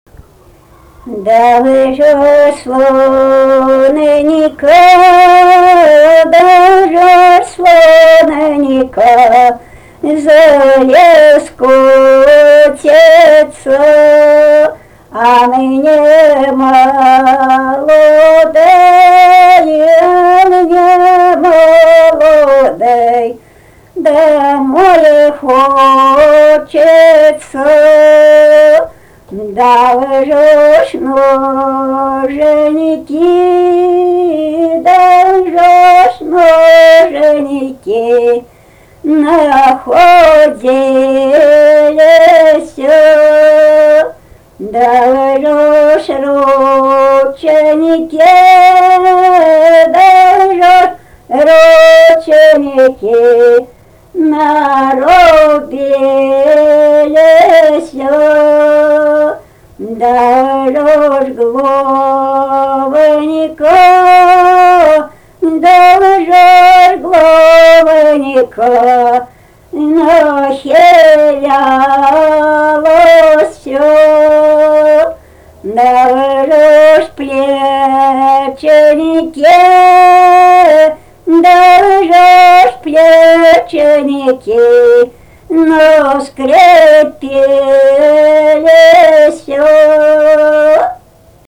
daina, kalendorinių apeigų ir darbo